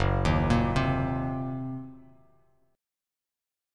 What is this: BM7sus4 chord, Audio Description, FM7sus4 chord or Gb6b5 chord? Gb6b5 chord